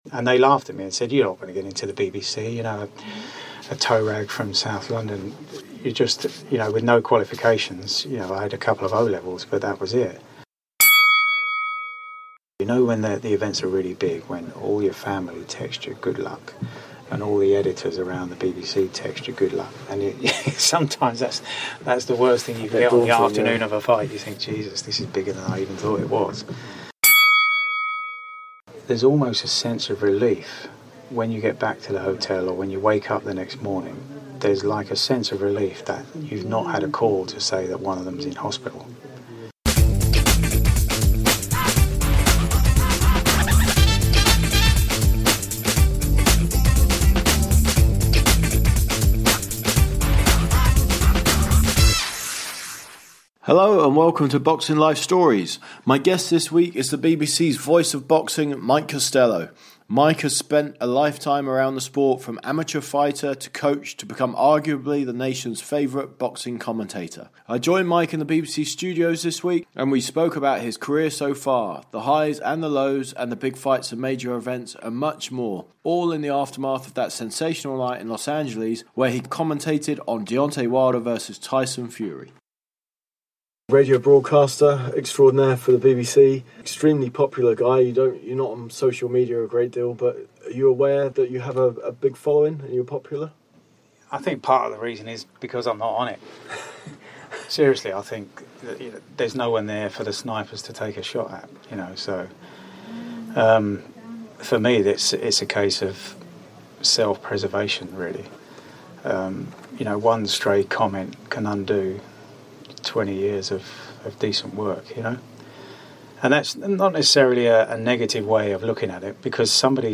I joined Mike in the BBC studios this week and we spoke about his career so far.